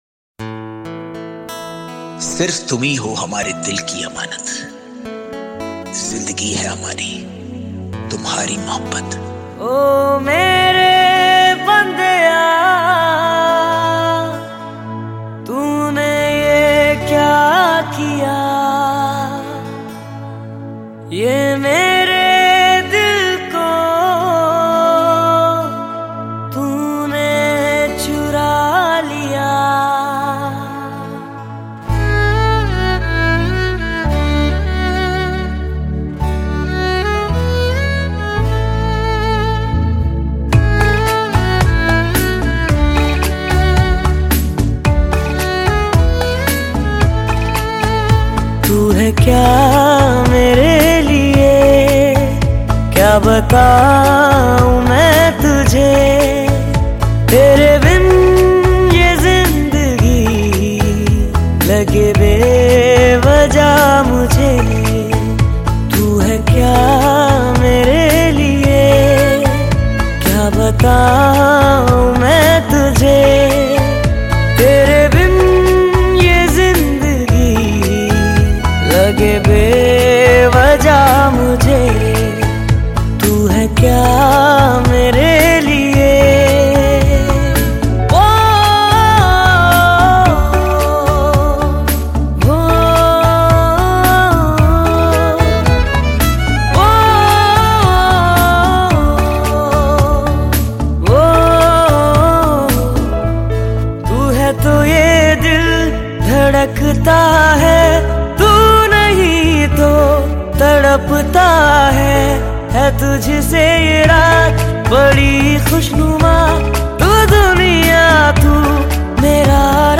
Hindi Pop Album Songs 2022